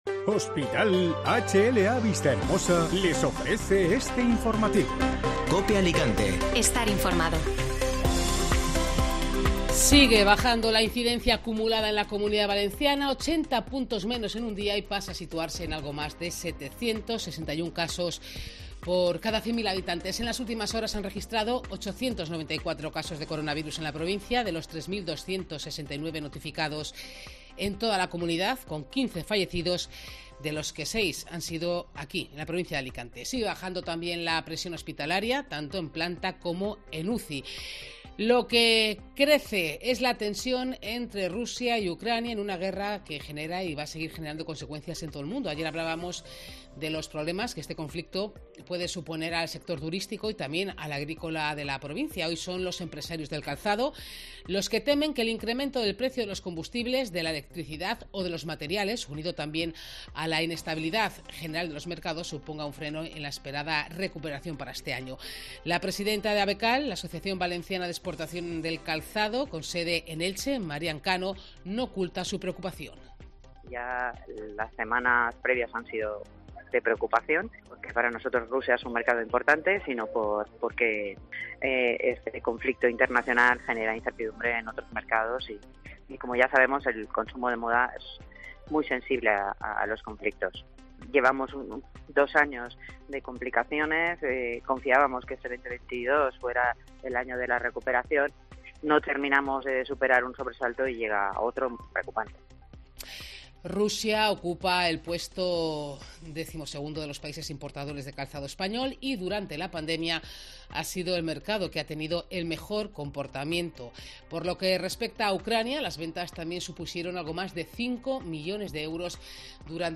Escucha las noticias y sonidos más destacados de este viernes en Alicante y provincia: Los agricultores claman por la supervivencia del sector en una nueva manifestación.